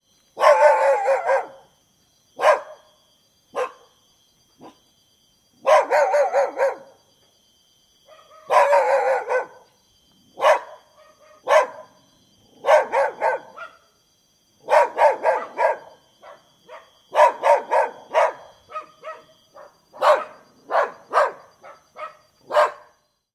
Звук лая собаки на даче